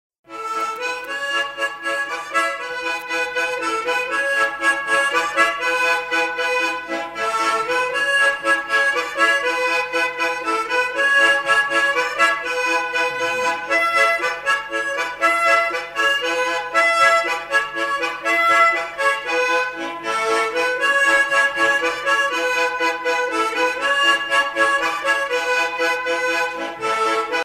danse : branle